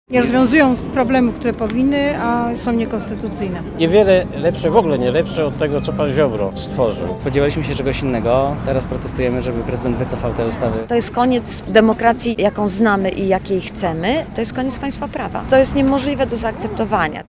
– mówili uczestnicy protestu w Warszawie.
protest sądy.mp3